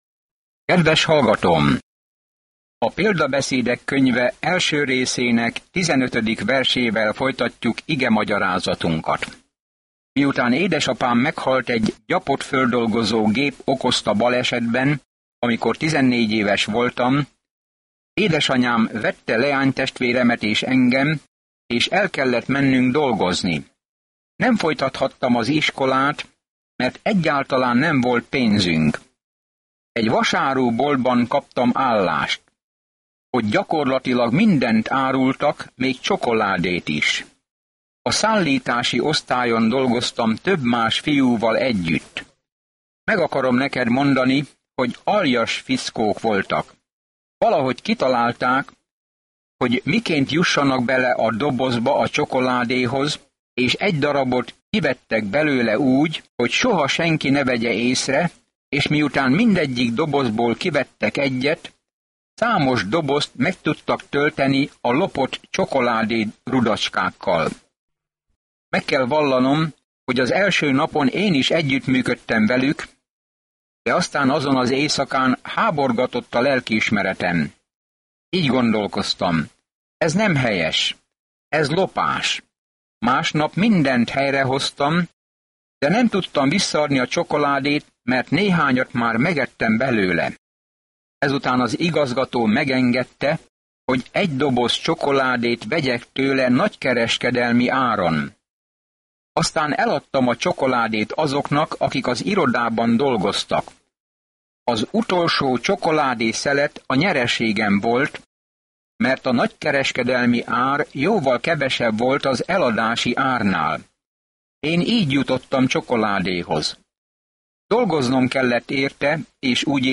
Szentírás Példabeszédek 1:15-33 Példabeszédek 2:1-6 Nap 2 Olvasóterv elkezdése Nap 4 A tervről A közmondások hosszú tapasztalatokból merített rövid mondatok, amelyek könnyen megjegyezhető módon tanítják az igazságot – olyan igazságok, amelyek segítenek bölcs döntéseket hozni. Napi utazás az Példabeszédek, miközben hallgatja a hangos tanulmányt, és olvassa el Isten szavának kiválasztott verseit.